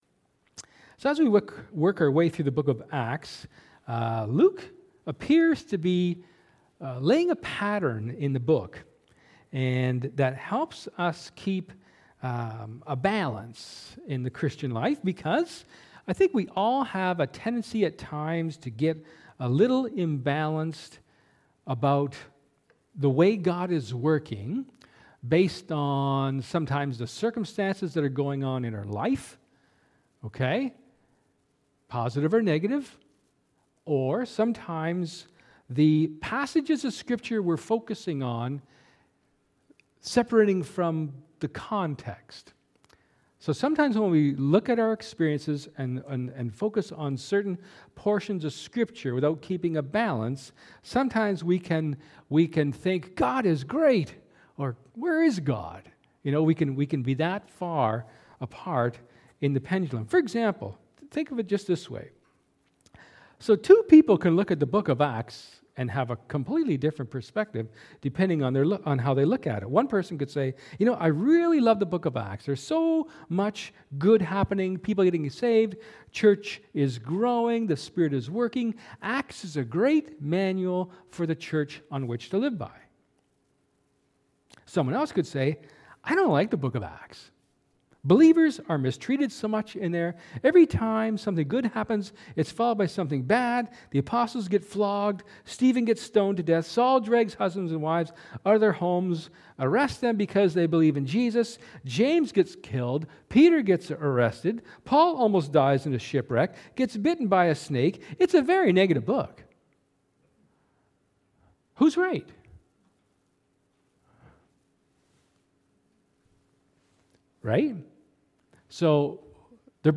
Luke 1:5-17 Service Type: Sermon